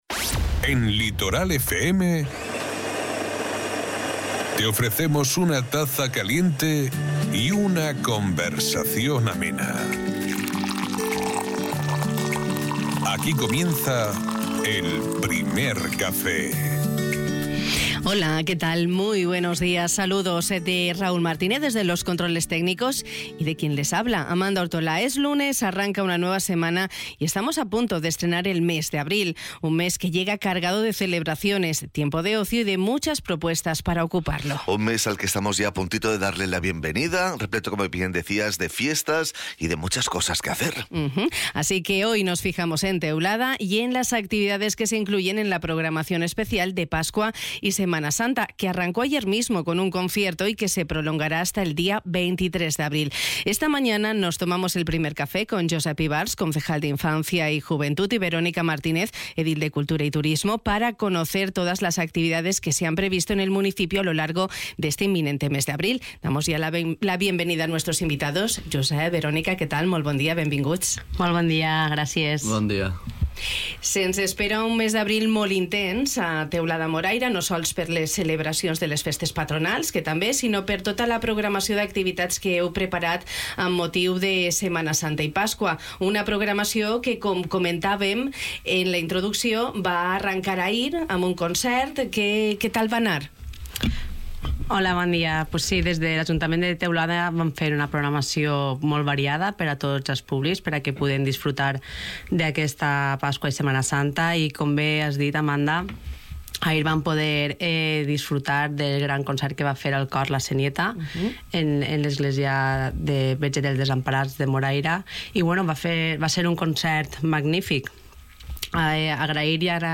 A punto de estrenar abril, un mes que llega al sur de la Marina Alta cargado de celebraciones, fiestas patronales, de tiempo de ocio y de muchas propuestas con el que ocuparlo, hemos dedicado nuestro Primer Café de hoy a las actividades que se incluyen en la programación especial de Pascua y Semana Santa que ha preparado Teulada. Una amplia programación que arrancó ayer mismo, con un concierto y que se prolongará hasta el 23 de abril, como nos han explicado nuestros invitados Josep Ivars, concejal de Infancia y Juventud, y Verónica Martínez, edil de Cultura y Turismo.